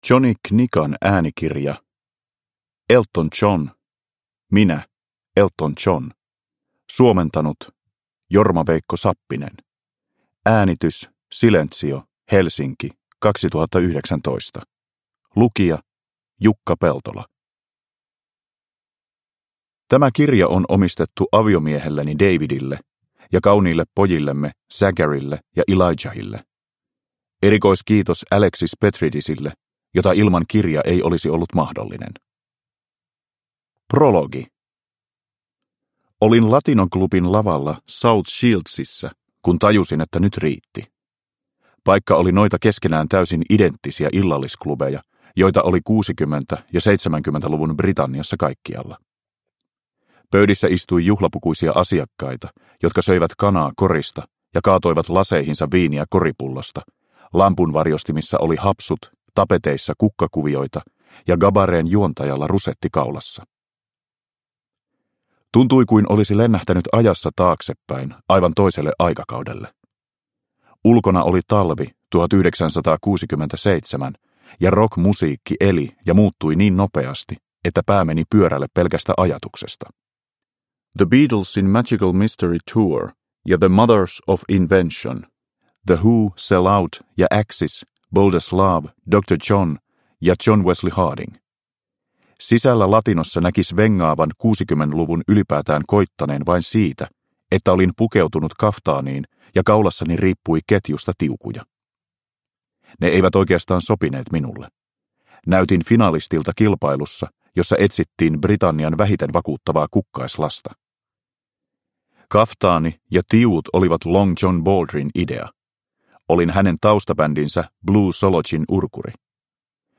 Minä Elton John – Ljudbok – Laddas ner
Uppläsare: Jukka Peltola